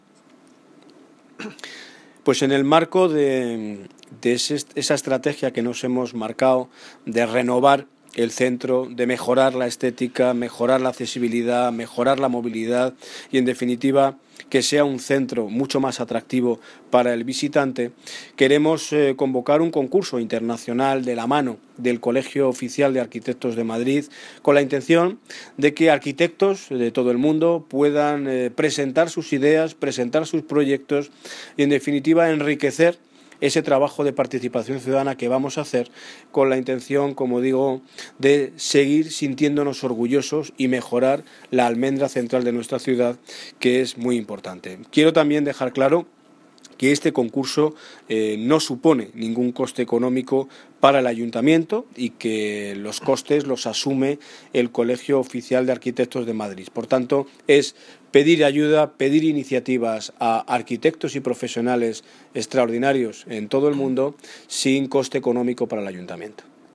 Audio de Daniel Ortiz, Alcalde de Móstoles